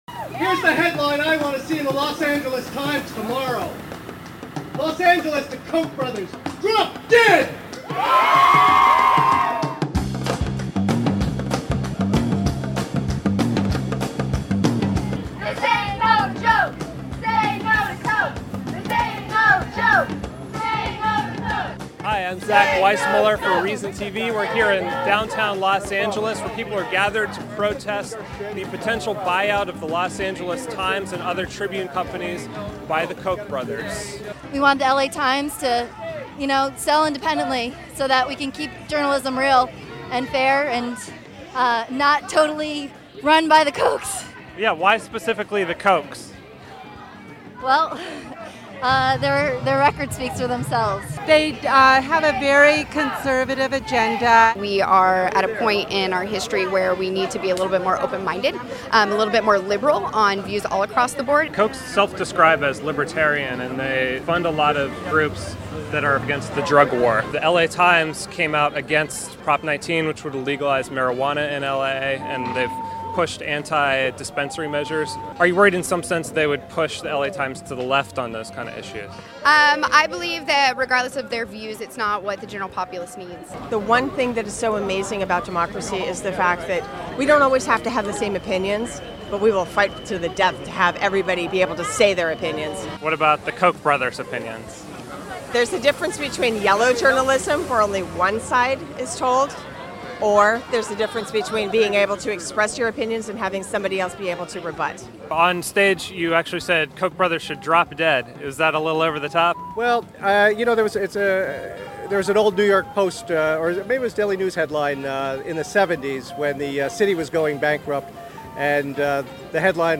Koch LA Times Buyout Enrages Protesters: What We Saw at the "Save Our News" Rally
Protesters gathered outside of the Los Angeles Times building on Wednesday to speak out against the potential sale of the newspaper and other Tribune Company properties to Koch Industries, the privately held company owned by Charles and David Koch. Reason TV asked why the potential sale was so upsetting to those gathered and what they thought about possible city council actions against the Times.